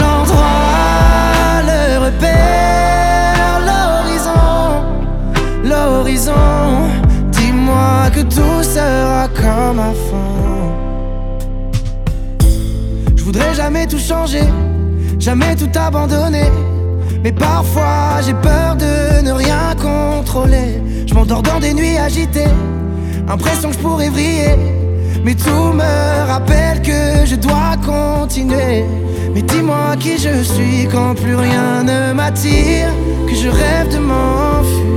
Жанр: Поп музыка
French Pop